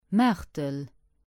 Martelange (French pronunciation: [maʁtəlɑ̃ʒ]; German: Martelingen; Luxembourgish: Maartel pronounced [ˈmaːtəl]